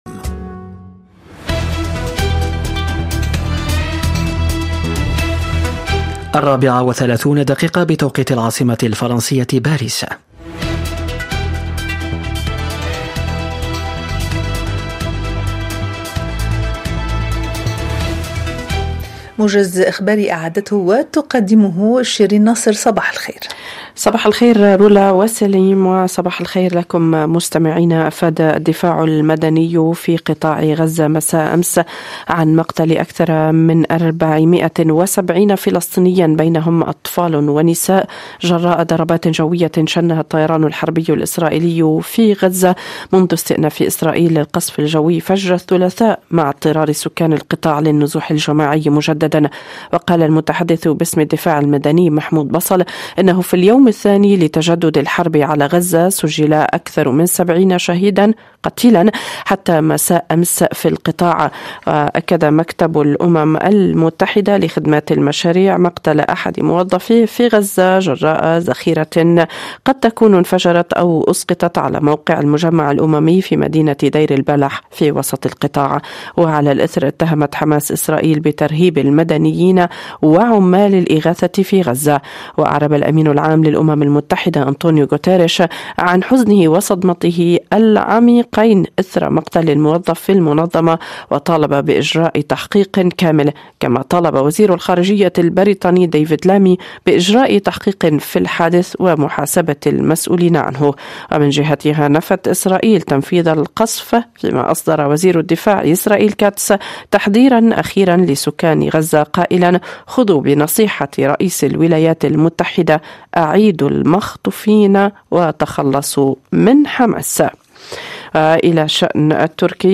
برامج مونت كارلو الدولية من تقديم صحفيين ومذيعين متخصصين وتعتمد في أغلبها على التواصل اليومي مع المستمع من خلال ملفات صحية واجتماعية ذات صلة بالحياة اليومية تهم المرأة والشباب والعائلة، كما أنها تشكل نقطة التقاء الشرق بالغرب والعالم العربي بفرنسا بفضل برامج ثقافية وموسيقية غنية.